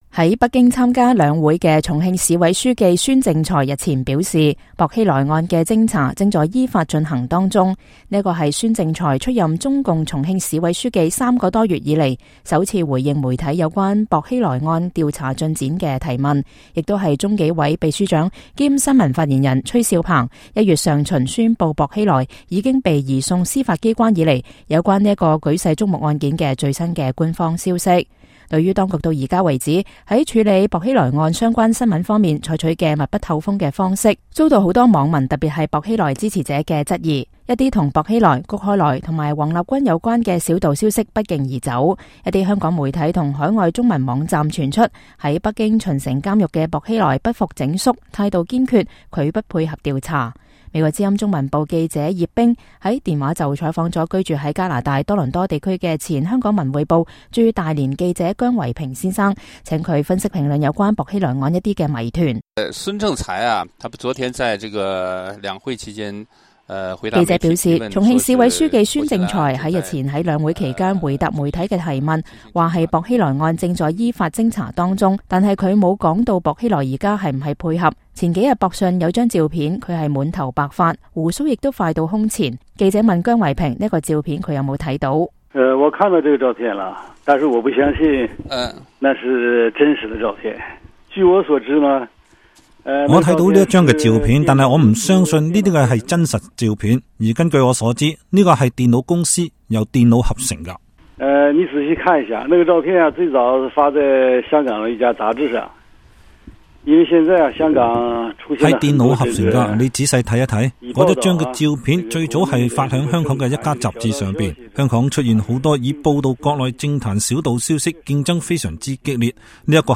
專訪